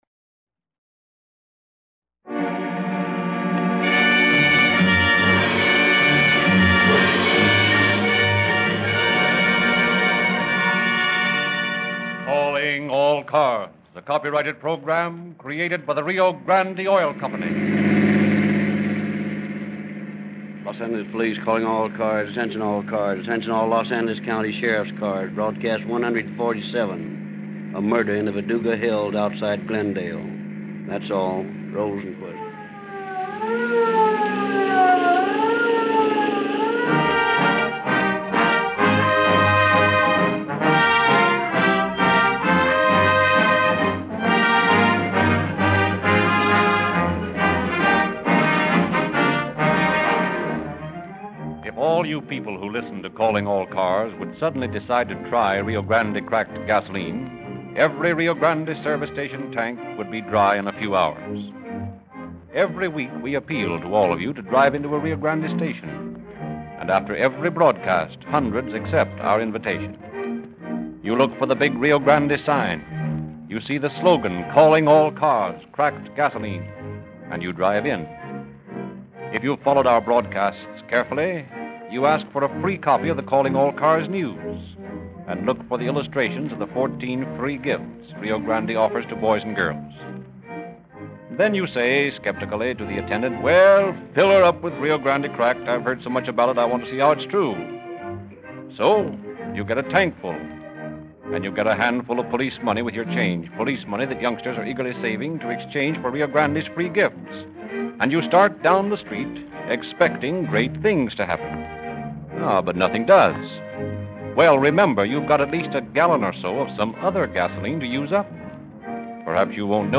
Calling All Cars Radio Program